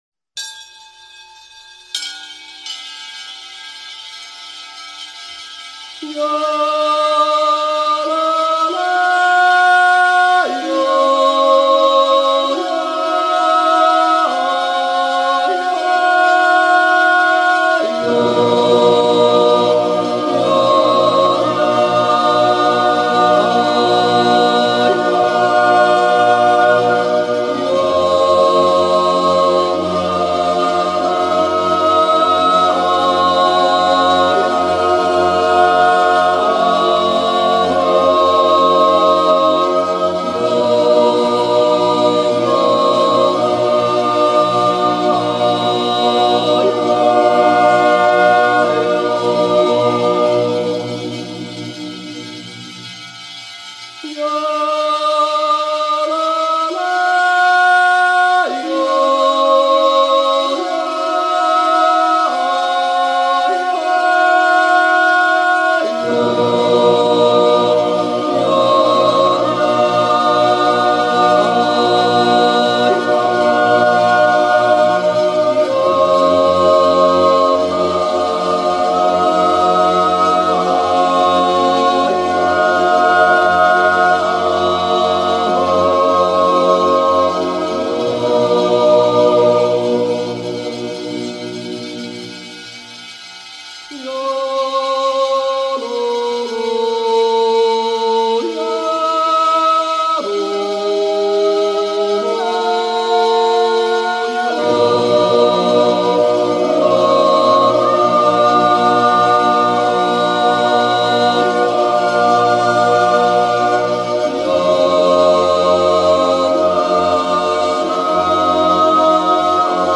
The yodel choir Urnäsch am Säntis (canton Appenzell Outer Rhodes in Eastern Switzerland) was founded in 1960. Natural yodel (Zäuerli) and yodel songs. The Kapelle Echo vom Säntis and the Handorgelduo Gebrüder Gähler play folk dances from the region.